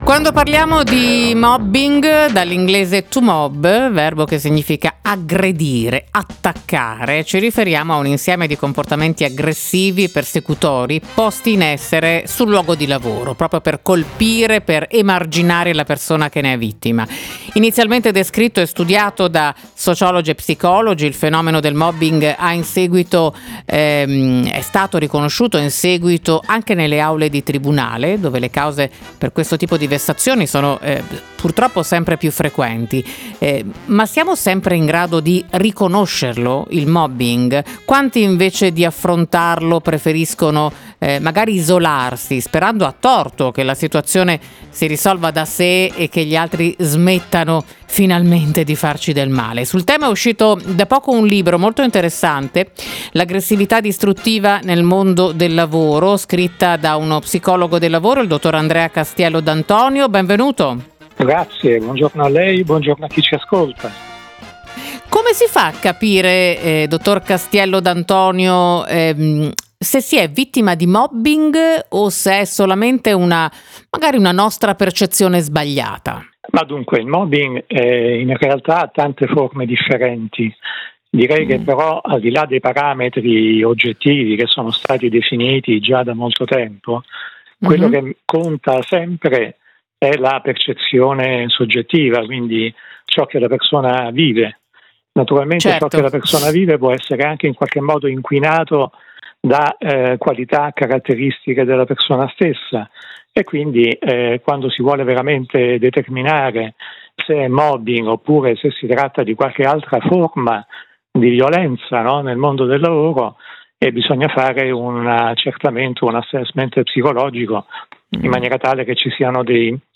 GIORNALE RADIO.